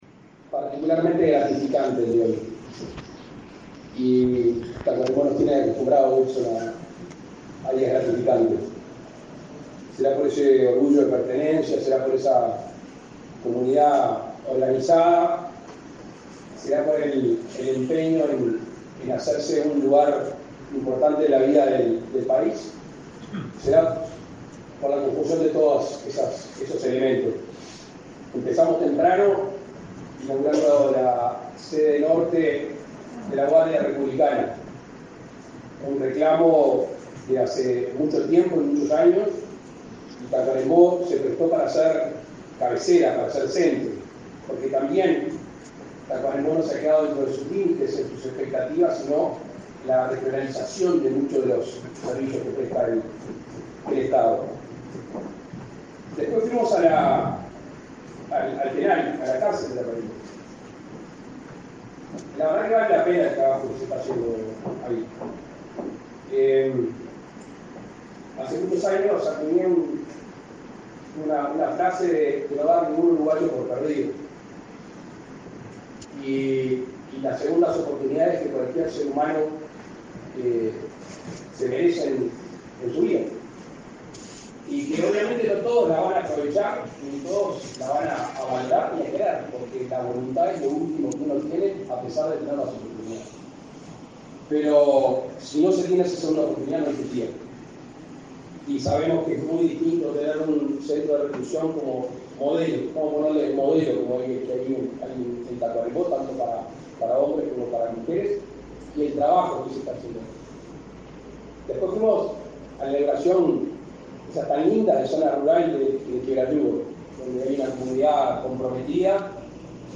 Palabras del presidente Lacalle Pou durante inauguración en hospital de Tacuarembó
En el marco de la inauguración del Instituto de Medicina Altamente Especializada (IMAE) Cardiológico, en el hospital de Tacuarembó, este 19 de mayo,